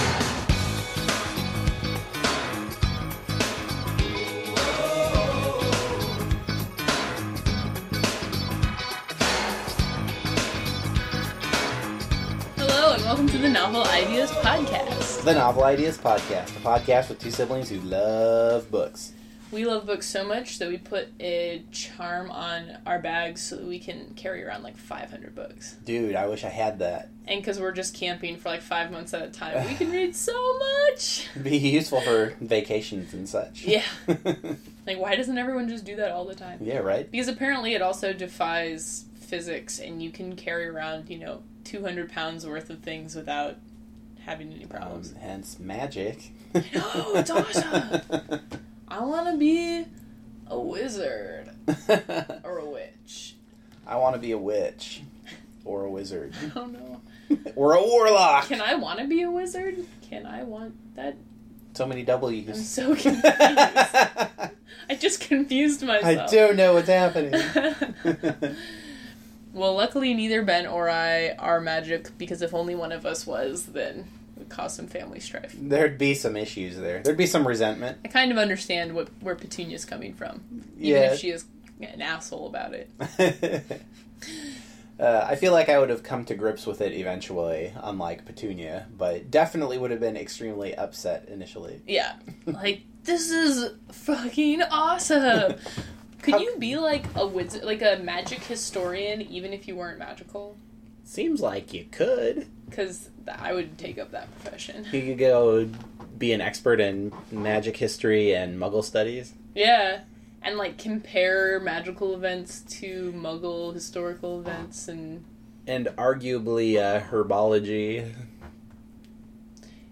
The music bump is a departure from the Potter related music of the previous six episodes, but is thematically appropriate.